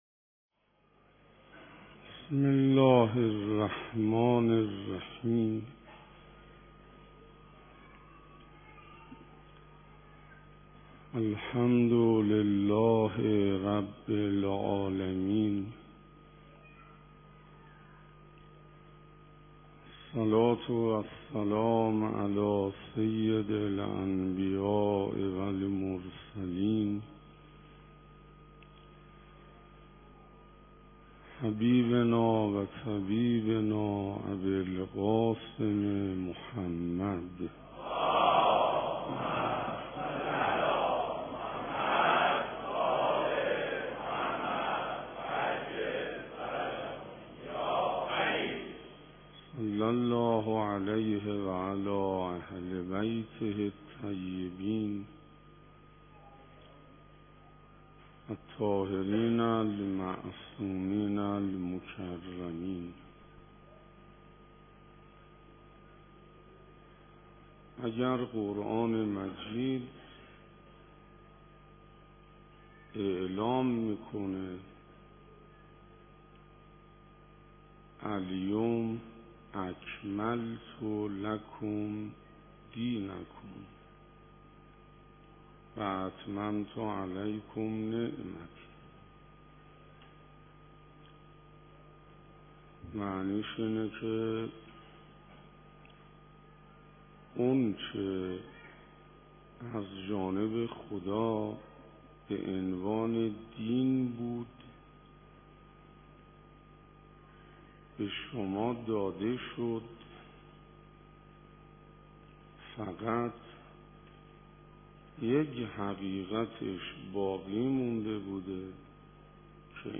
بشنوید/ عیدسعید غدیر خم 94 در مسجد حضرت امیر(ع)